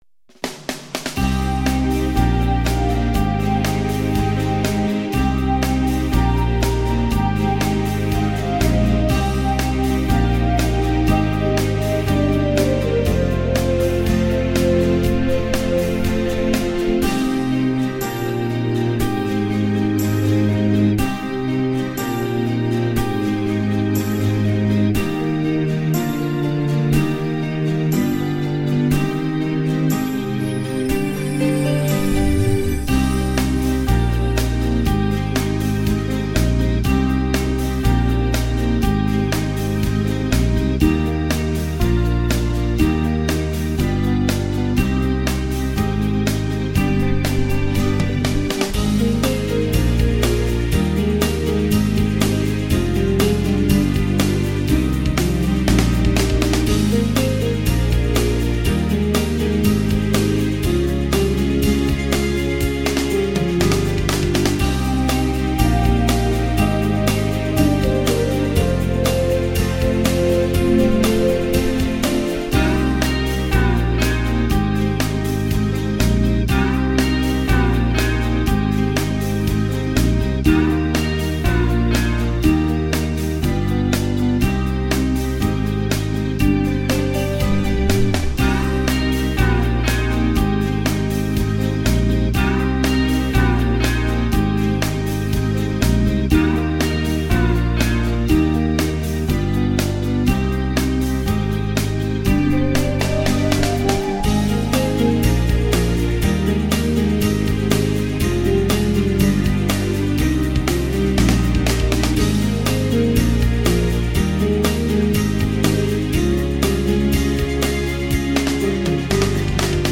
• Категория: Детские песни
караоке